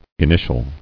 [in·i·tial]